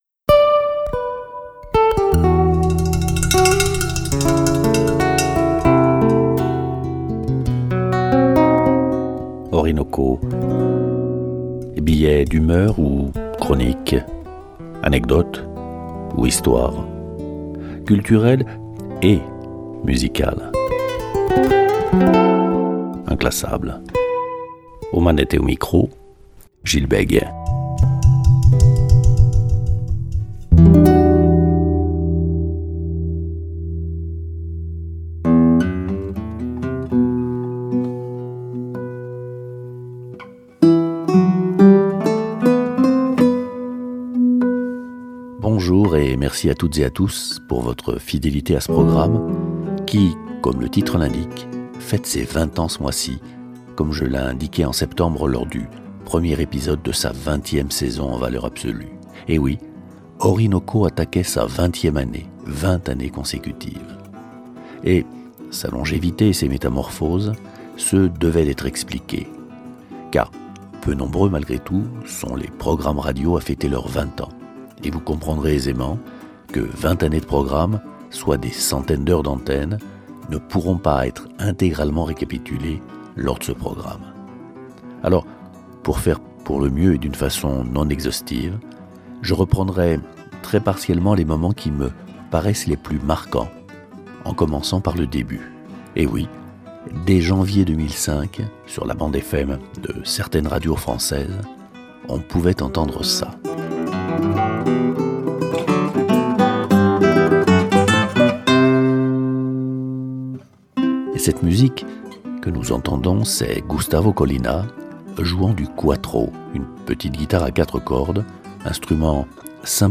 Une boite à boutons révélant ces secrets Contes et conteurs Sans oublier une part importante laissée aux fables, légendes et poésies ainsi qu’une programmation musicale toujours en adéquation avec les thèmes développés dans chaque numéro.